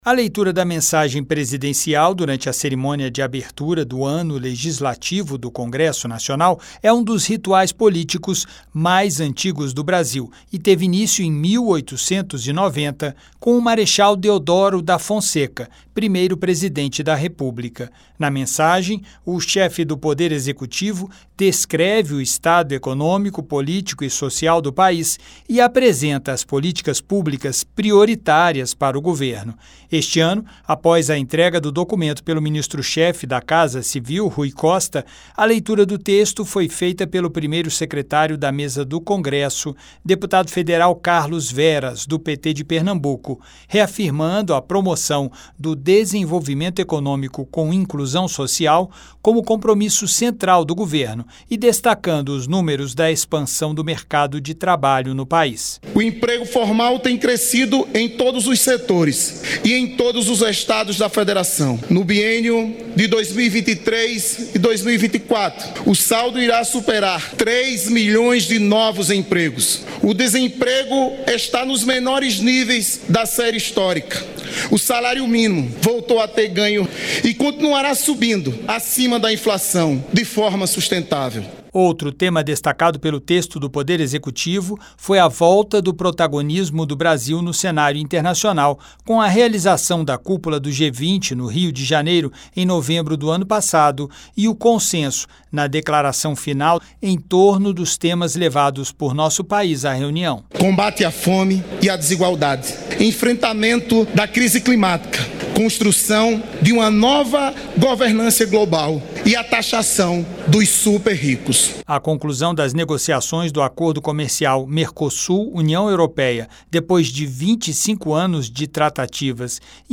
Também ganhou destaque a aprovação da reforma tributária pelo Congresso. A mensagem do presidente Luiz Inácio Lula da Silva foi lida pelo primeiro-secretário da Mesa do Congresso, deputado Carlos Veras (PT-PE).